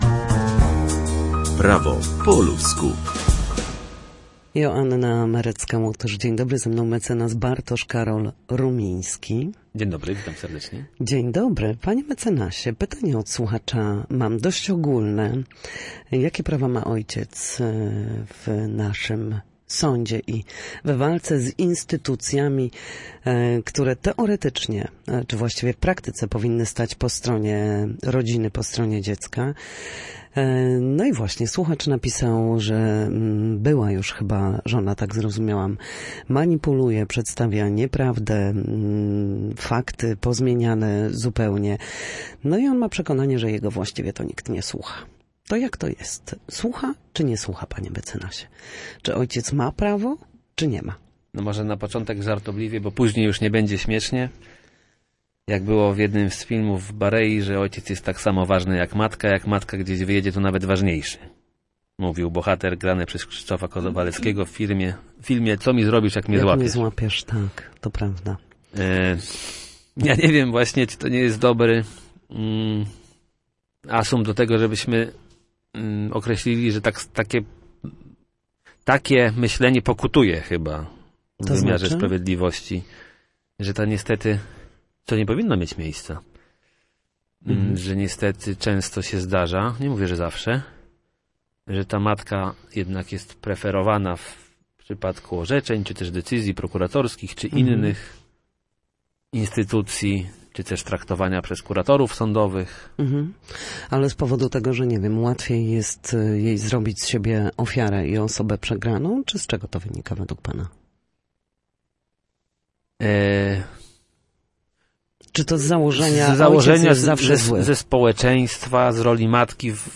W każdy wtorek o godzinie 13:40 na antenie Studia Słupsk przybliżamy meandry prawa. W naszym cyklu prawnym gościmy ekspertów, którzy odpowiadają na jedno konkretne pytanie związane z zachowaniem w sądzie lub podstawowymi zagadnieniami prawnymi.